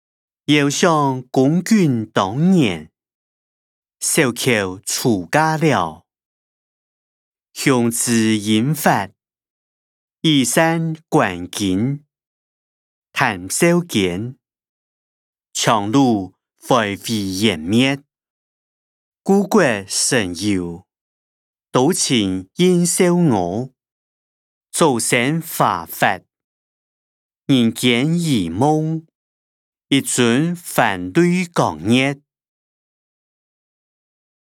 詞、曲-念奴嬌•赤壁懷古音檔(四縣腔)